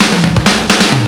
02_23_drumbreak.wav